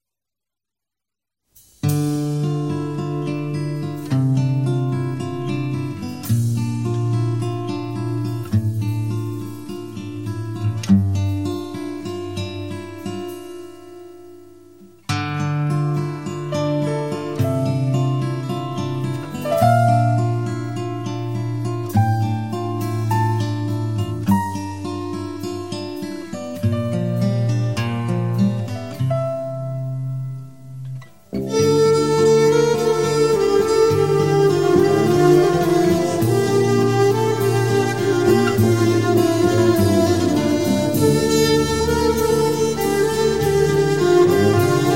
Folk / Celta/ World Music